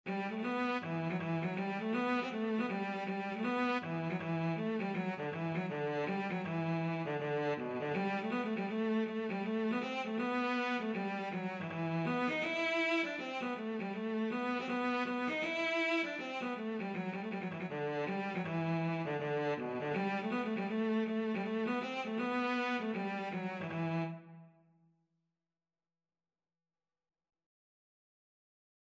Traditional Trad. I Dreamt of My Love (Irish Folk Song) Cello version
E minor (Sounding Pitch) (View more E minor Music for Cello )
4/4 (View more 4/4 Music)
B3-E5
Cello  (View more Easy Cello Music)
Traditional (View more Traditional Cello Music)